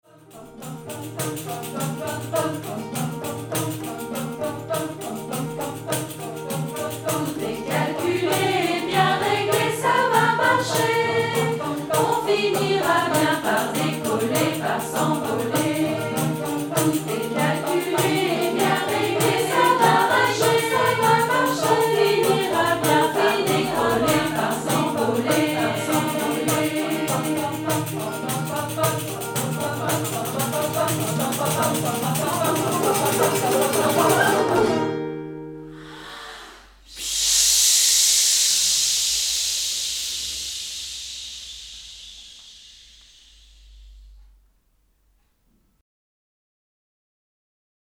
Une mélodie qui peut sonner en canon à quatre voix, un ostinato rythmique à deux voix, un texte humoristique qui évoque une machine qui s’emballe…